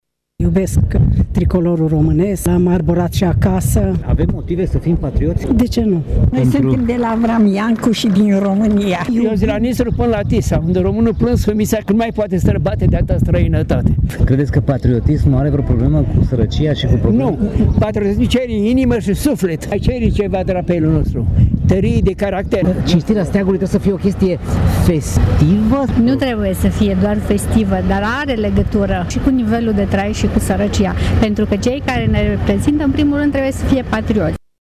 Cei care au asistat, însă, s-au exprimat decis în favoarea unui patriotism sănătos, indiferent de starea materială a românilor și de plecarea sau rămânerea în țară: